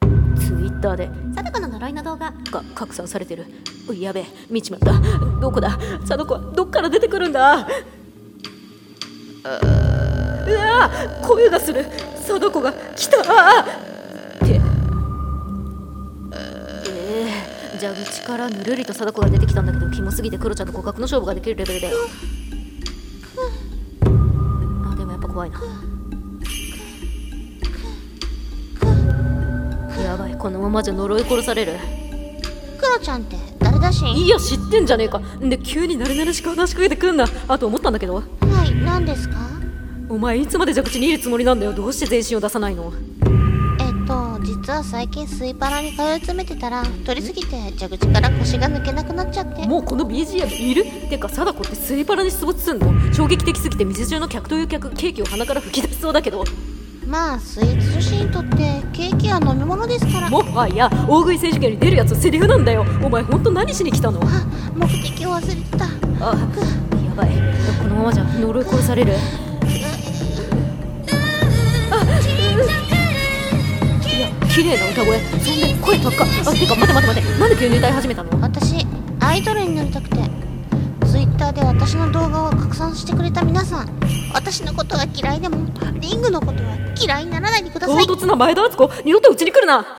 【声劇】貞子の呪い【ギャグ声劇】